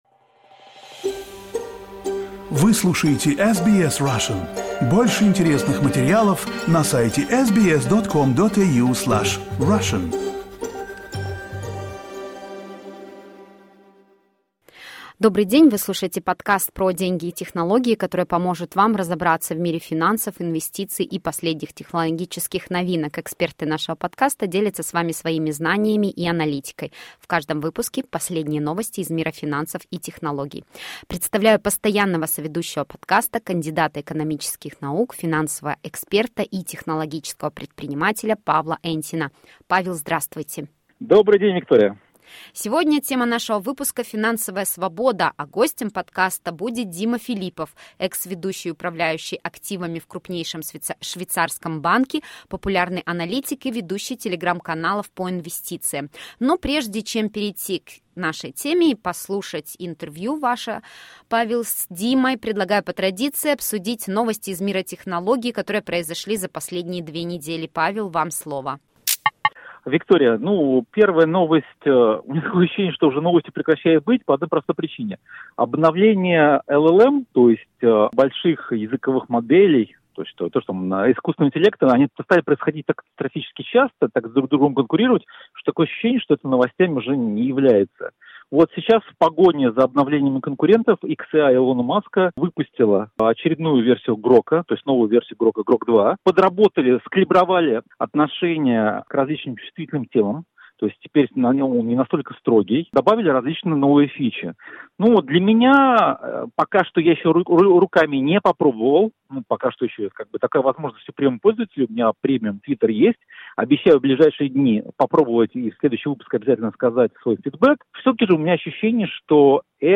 Money and technology will help you understand the world of finance, investments and the latest technological innovations. Our guests share their knowledge and analysis with you.